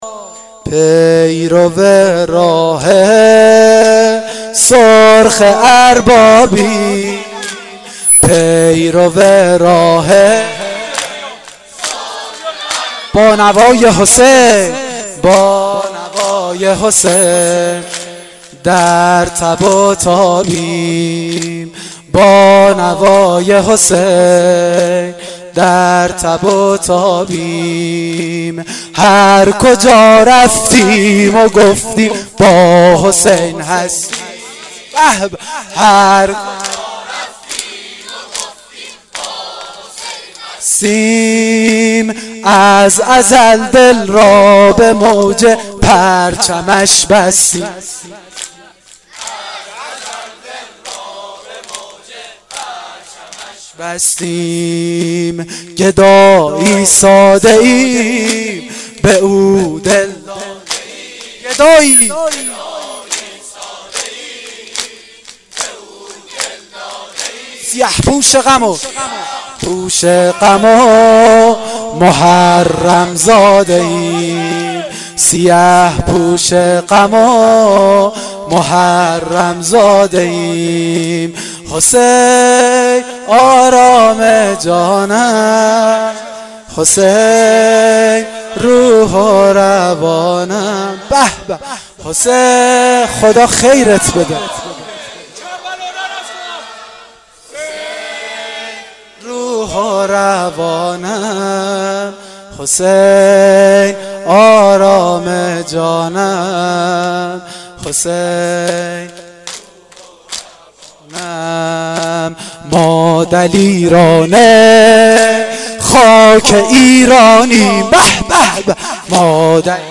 دم پایانی شب عاشورا محرم1393